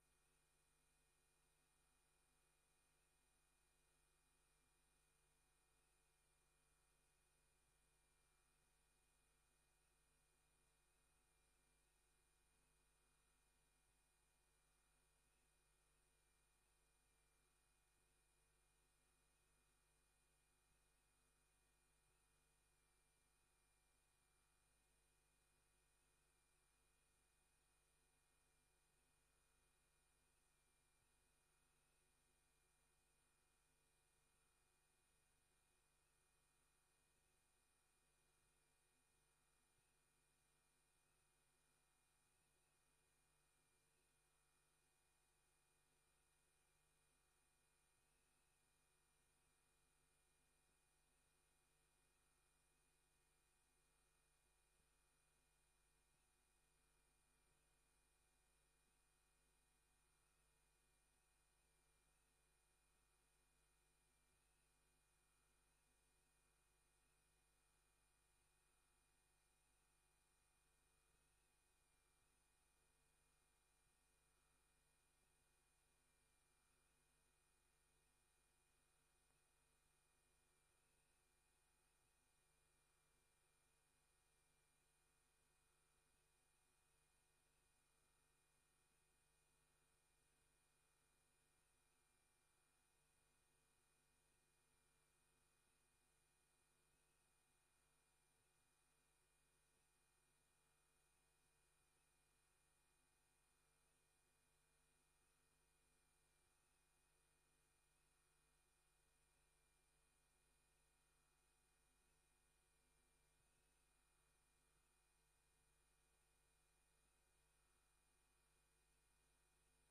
Download de volledige audio van deze vergadering
Hierbij nodig ik u uit tot het bijwonen van een openbare vergadering van de commissie op maandag 10 februari 2025, om 19:30 uur in kamer 83 van het gemeentehuis te Emmeloord.